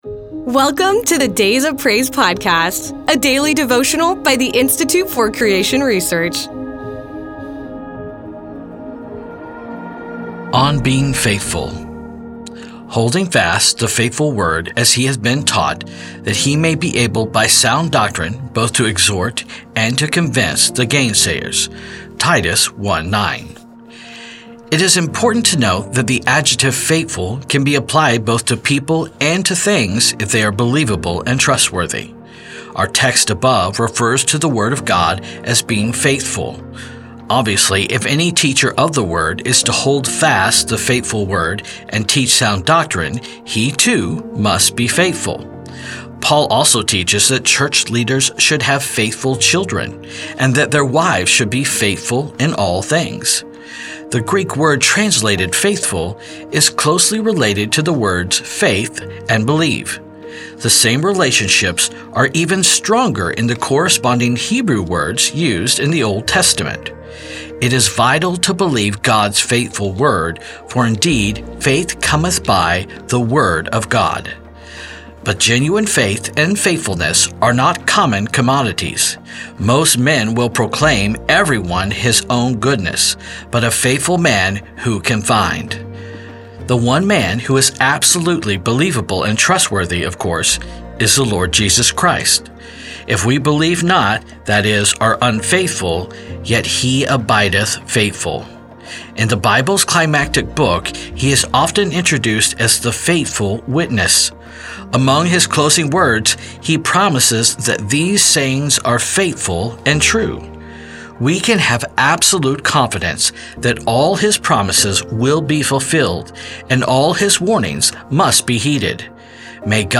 faithful faith doctrine Word of God Jesus Christ biblical truth devotional